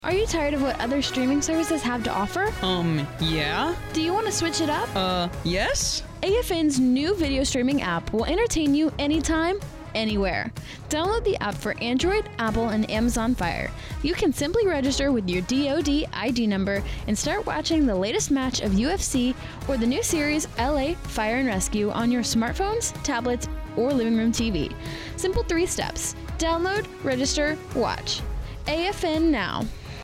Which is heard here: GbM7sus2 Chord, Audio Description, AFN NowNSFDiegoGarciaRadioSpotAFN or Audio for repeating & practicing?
AFN NowNSFDiegoGarciaRadioSpotAFN